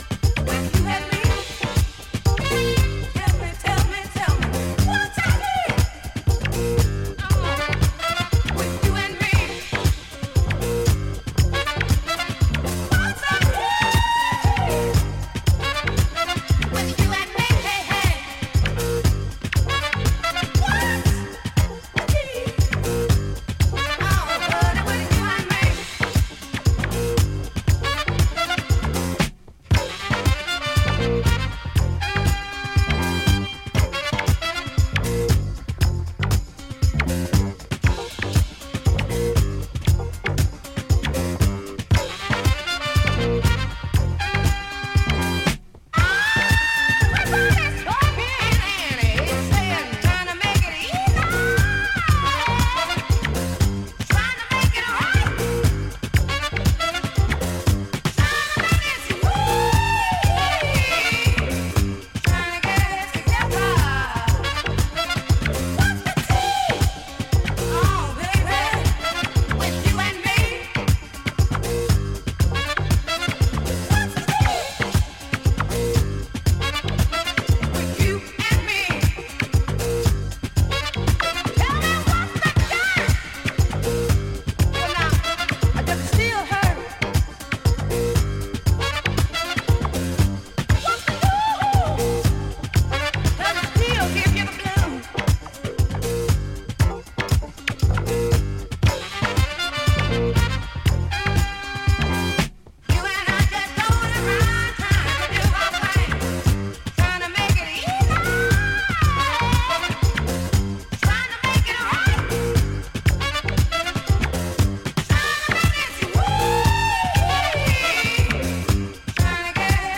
rooted in soul, jazz and groove.
signature flair for rhythmic depth and dancefloor flow